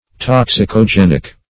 Meaning of toxicogenic. toxicogenic synonyms, pronunciation, spelling and more from Free Dictionary.
Search Result for " toxicogenic" : The Collaborative International Dictionary of English v.0.48: Toxicogenic \Tox`i*co*gen"ic\, a. [See Toxic , and -genic .]